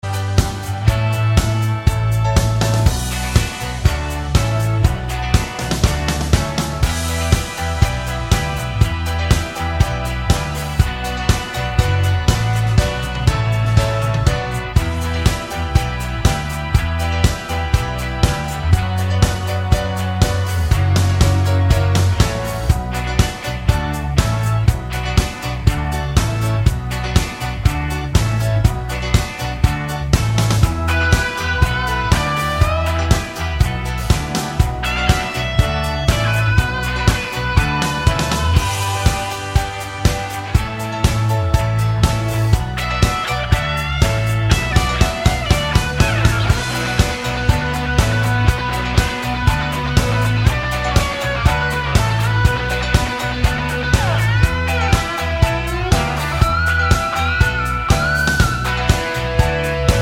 no Backing Vocals Rock 3:22 Buy £1.50